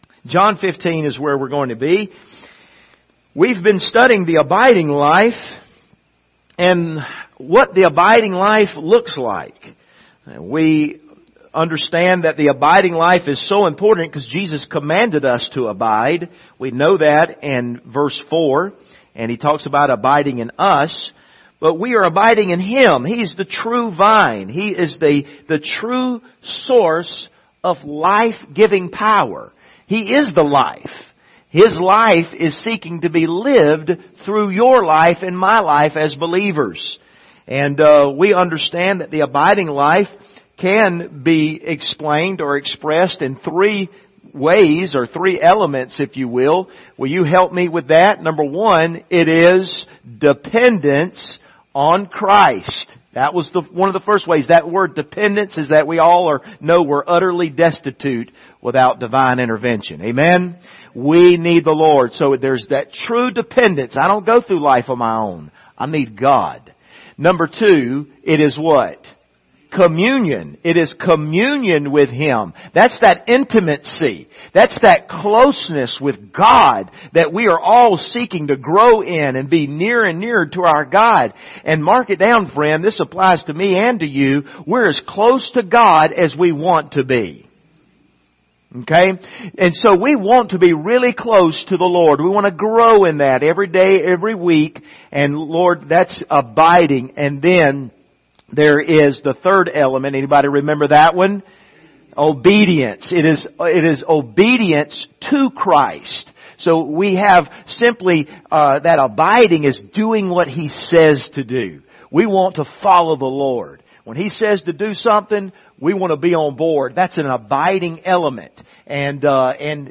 Passage: John 15:8-17 Service Type: Sunday Morning Topics